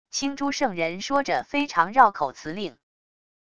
青珠圣人说着非常绕口辞令wav音频生成系统WAV Audio Player